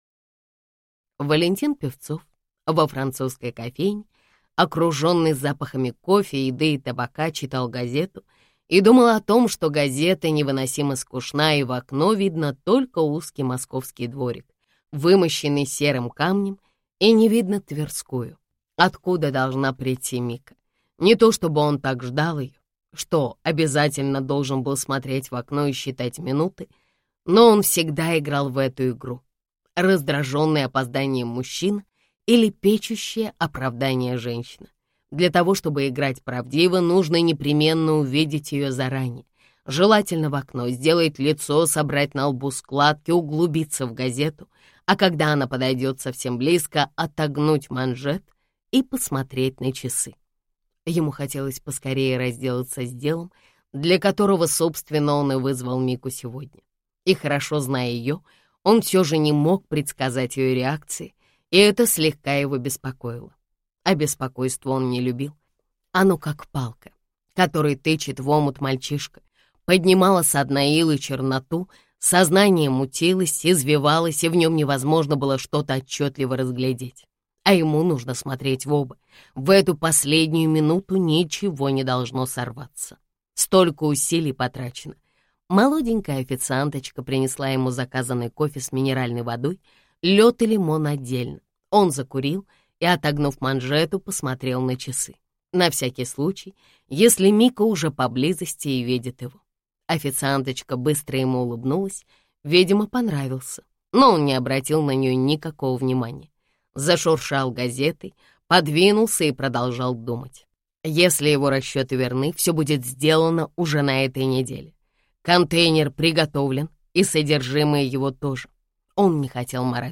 Аудиокнига Детектив на пороге весны | Библиотека аудиокниг